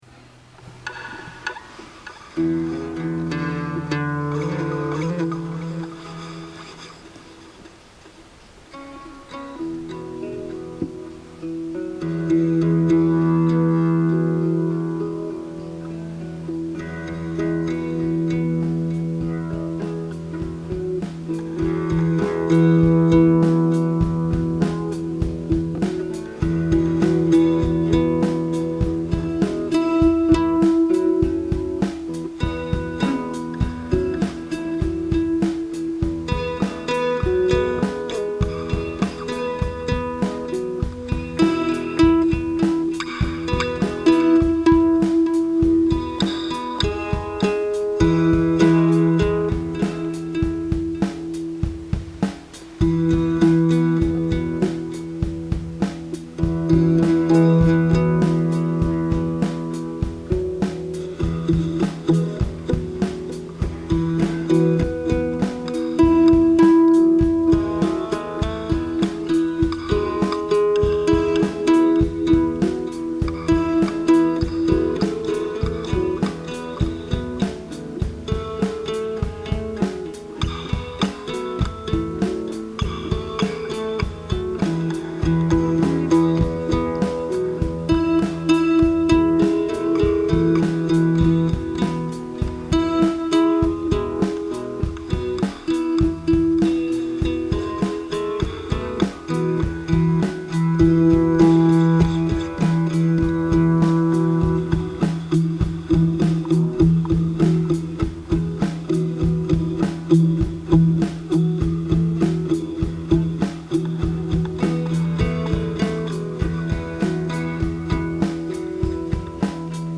2 guitar tracks with drum track
Recorded on iphone with 4-track. 2 guitar tracks with drum track minimal mixing with Garageband